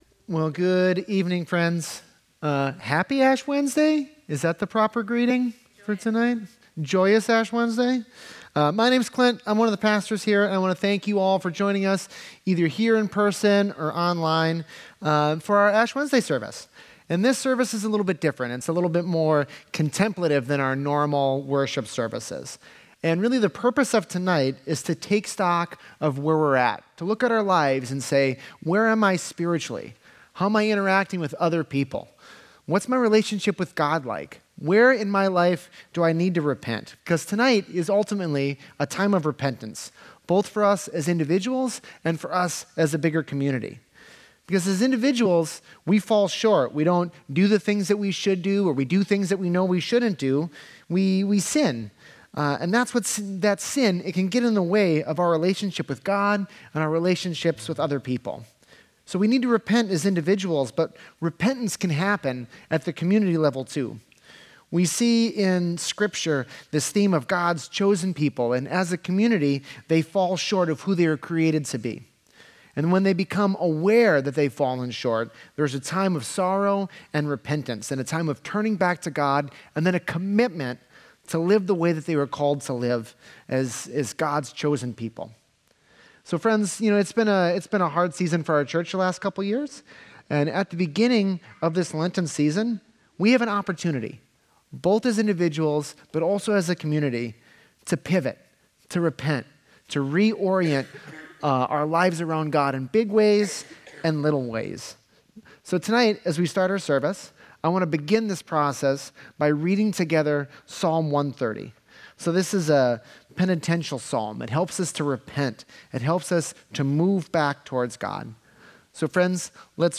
Ash Wednesday Service - The Vineyard Church